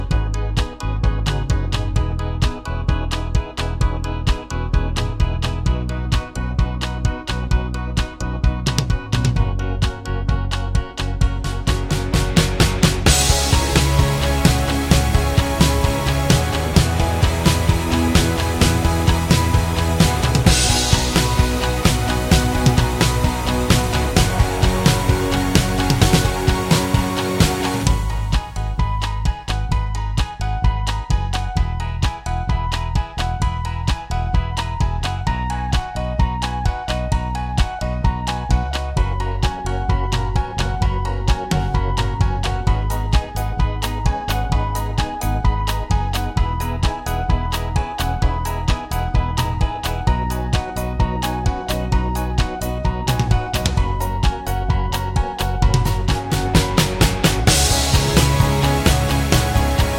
Minus Main Guitar For Guitarists 3:30 Buy £1.50